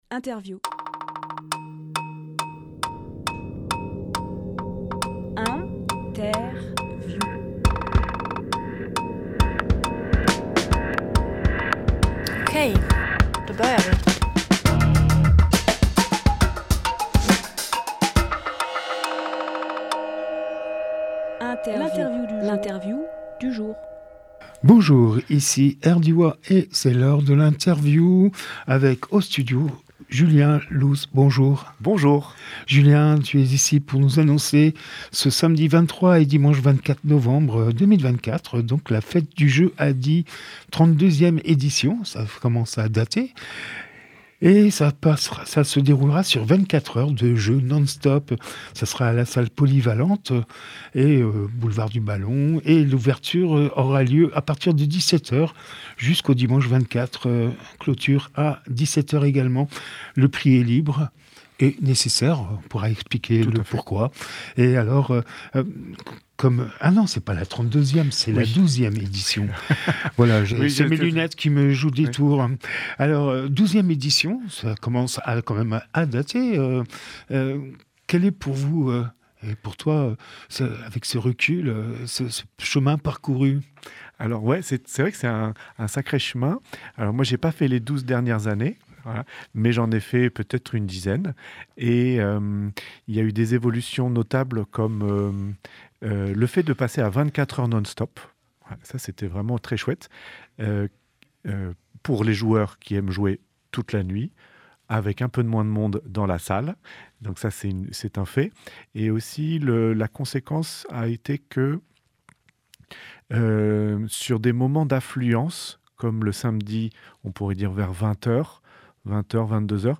Emission - Interview Fête du Jeu 24 heures non-stop Publié le 15 novembre 2024 Partager sur…
13.11.24 lieu : studio Rdwa durée